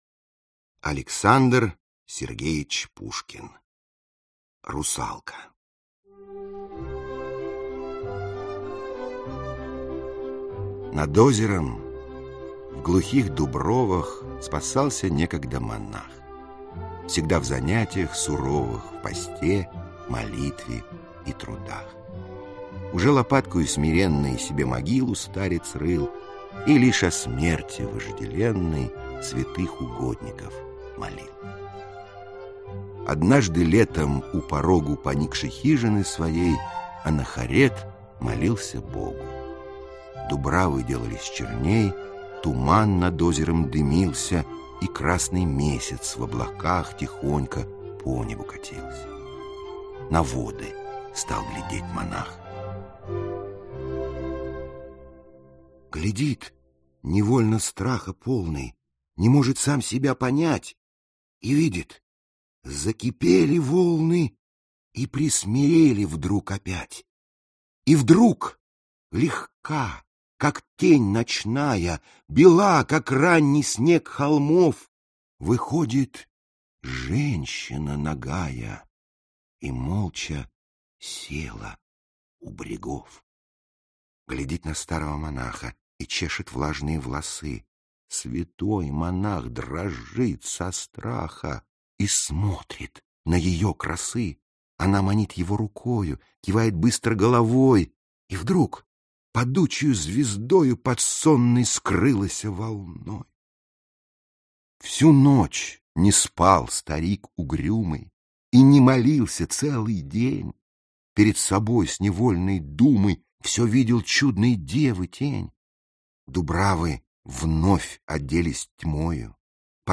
Аудиокнига Поэзия русского эроса | Библиотека аудиокниг
Aудиокнига Поэзия русского эроса Автор Сборник Читает аудиокнигу Михаил Горевой.